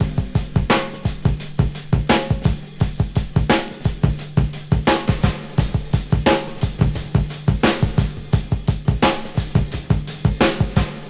• 86 Bpm Breakbeat D Key.wav
Free drum loop sample - kick tuned to the D note.
86-bpm-breakbeat-d-key-xjE.wav